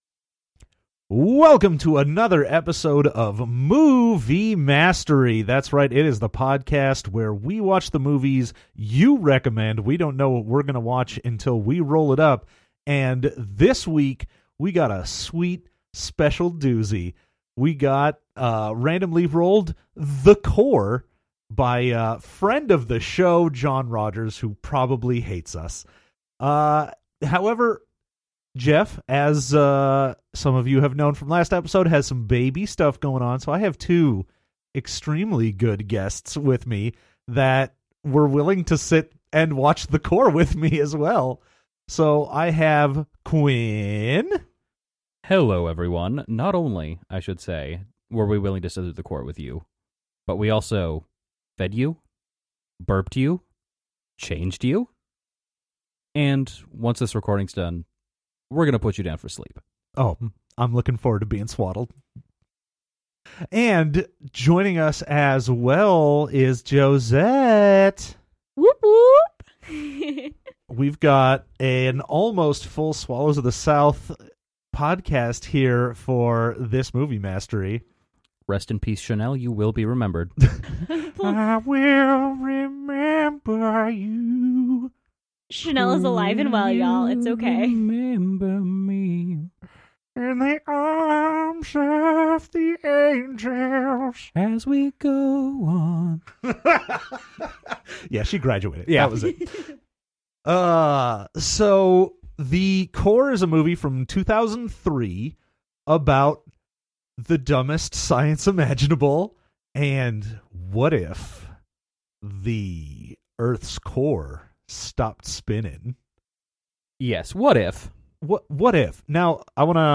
It’s another week of special guest hosts but this time you get twice the guests for half the cost!